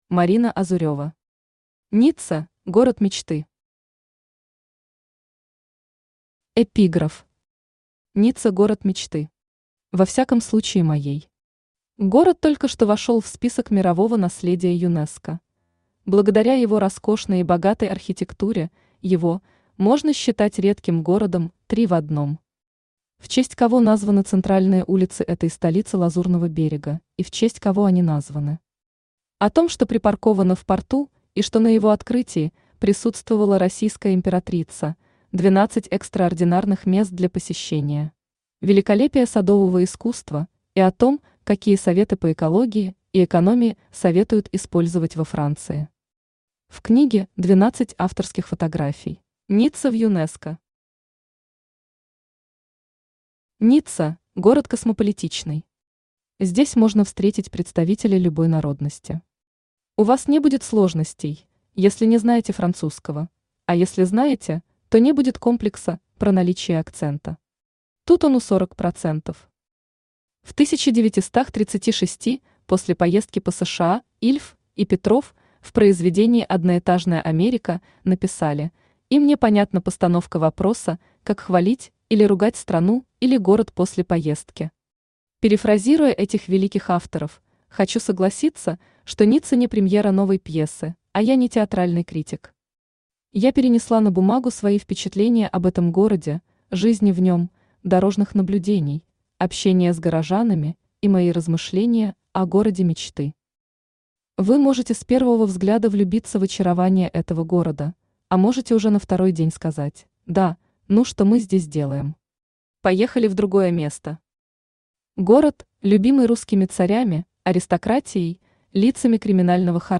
Аудиокнига Ницца – город мечты | Библиотека аудиокниг
Aудиокнига Ницца – город мечты Автор Марина Азурева Читает аудиокнигу Авточтец ЛитРес.